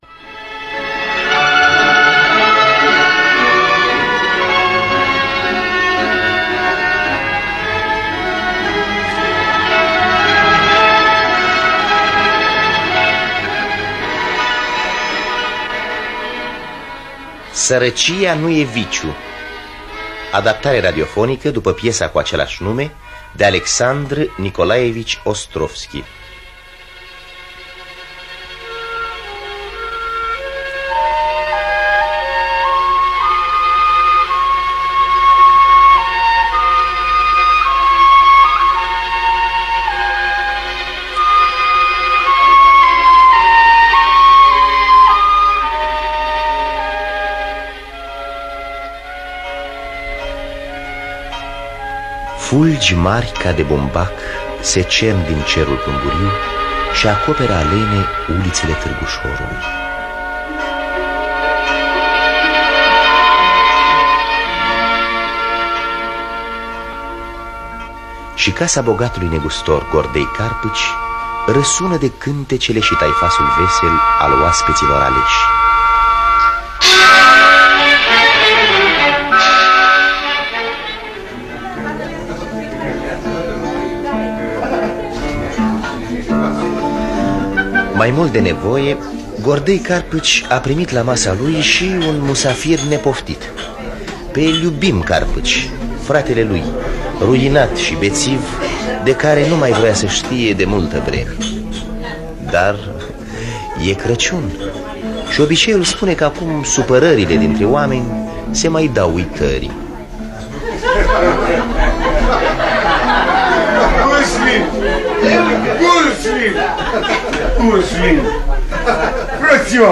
Sărăcia nu e viciu de A.N. Ostrovski – Teatru Radiofonic Online